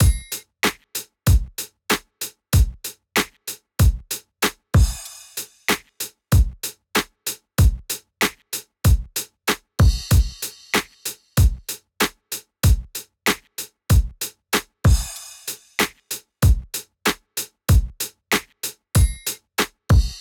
To demonstrate I’ll use a simple drum loop, here it is dry:
ir-example-drum-loop-dry.wav